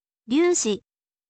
ryuushi